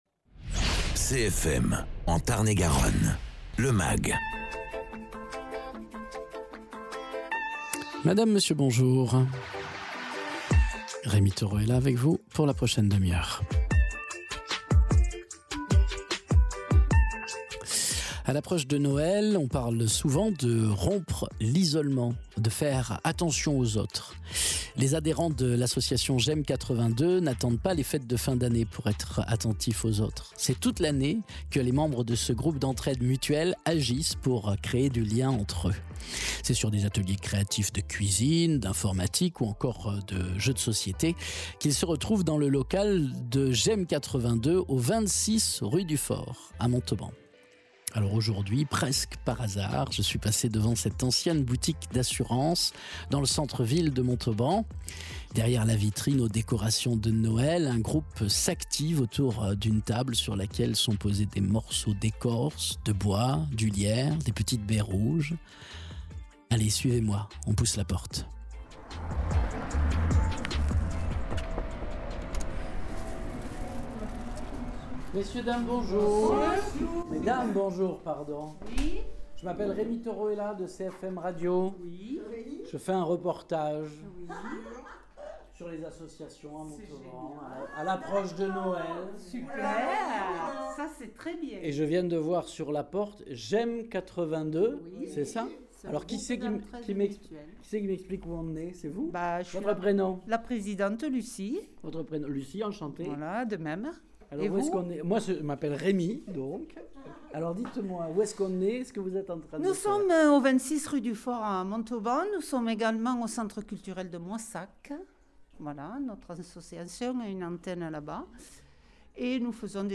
Reportage au local de J’M 82 à Montauban